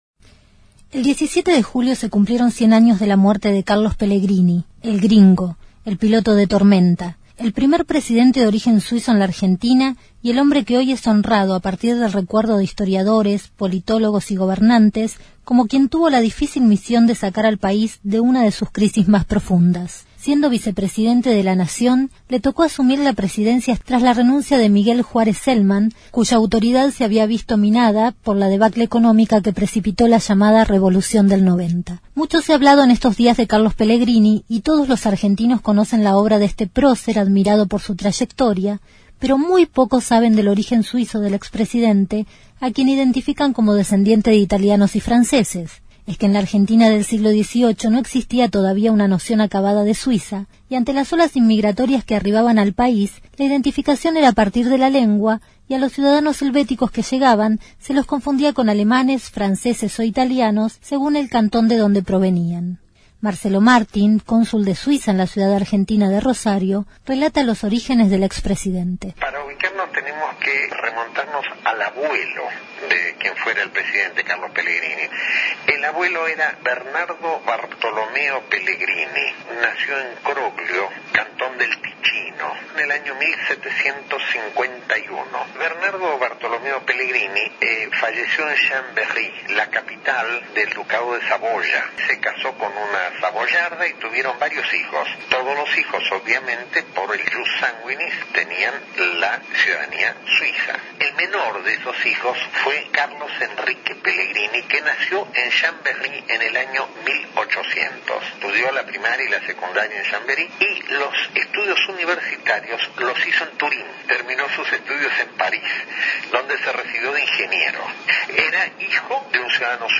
El 17 de julio se cumplieron 100 años de la muerte de Carlos Pellegrini, el primer Presidente de origen suizo en la Argentina. Hoy es recordado como el «piloto de tormentas» que tuvo la difícil misión de sacar al país de una de sus crisis más profundas y promotor del industrialismo nacional. Un reportaje
desde Buenos Aires para swissinfo.